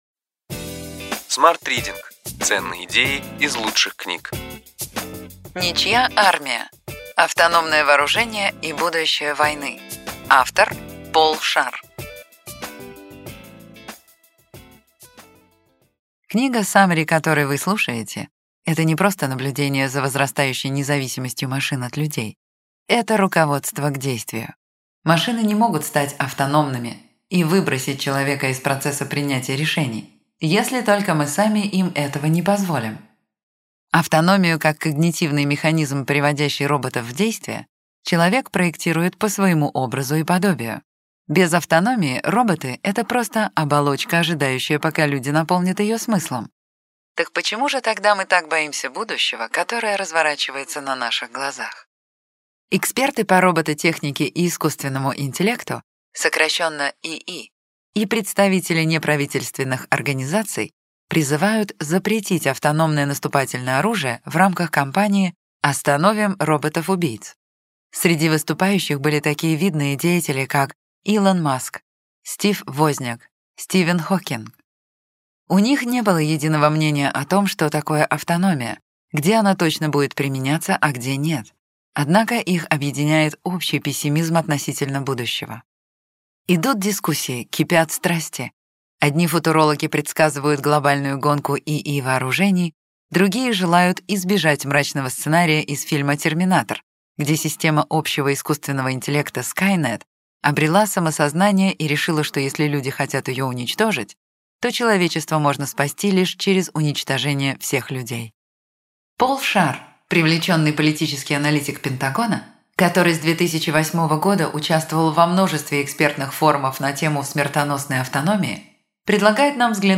Аудиокнига Ключевые идеи книги: Ничья армия: автономное вооружение и будущее войны.